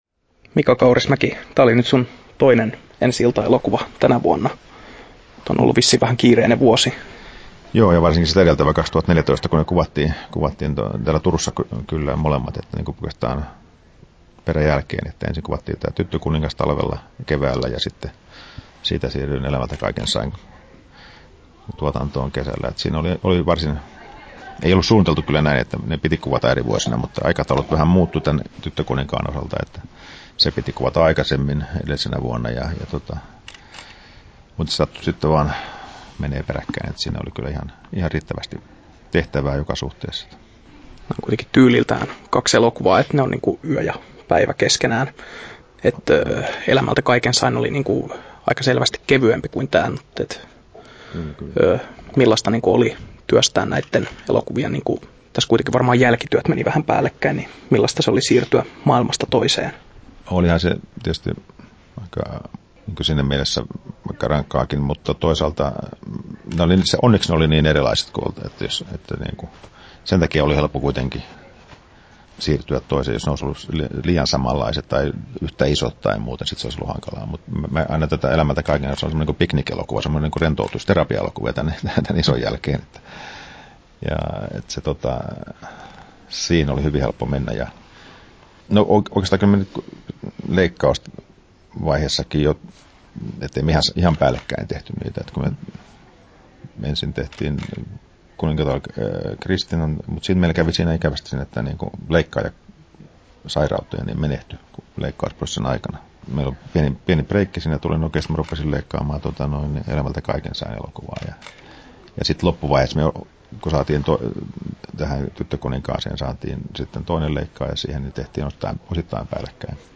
Haastattelussa Mika Kaurismäki Kesto